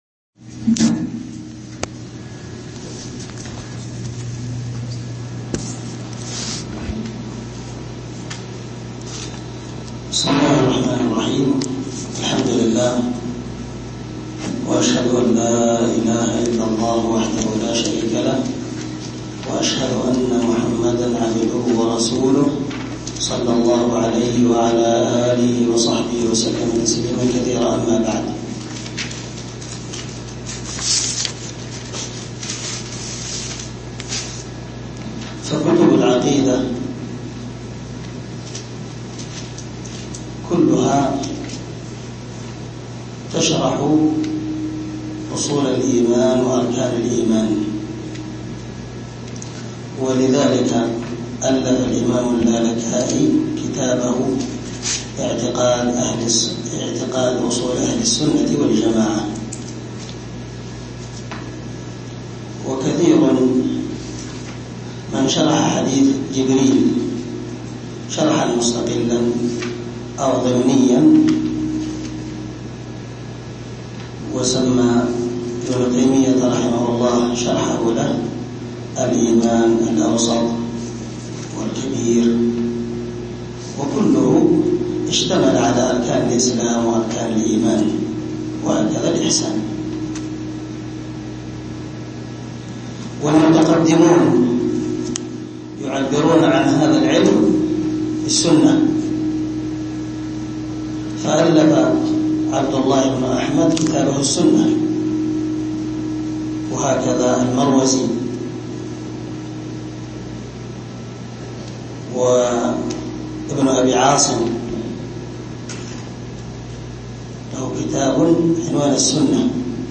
عنوان الدرس: الدرس الأول
دار الحديث- المَحاوِلة- الصبيحة.